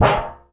metal_tin_impacts_hit_hard_05.wav